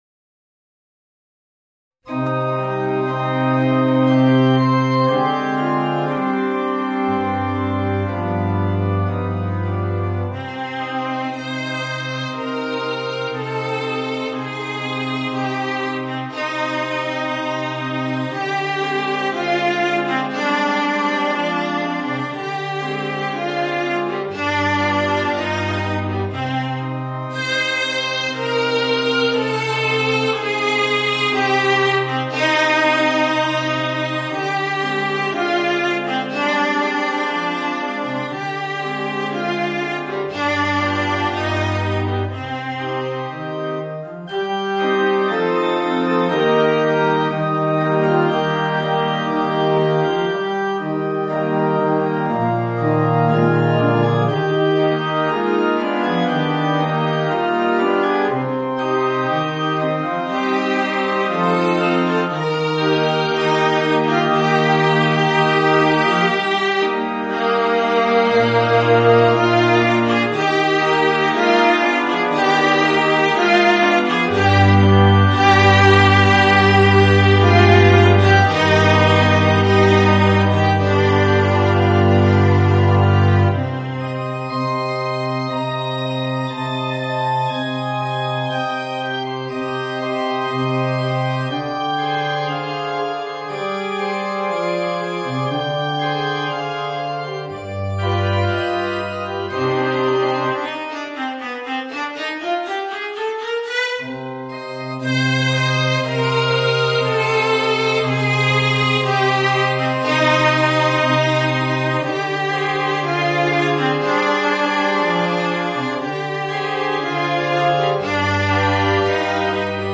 Voicing: Viola and Organ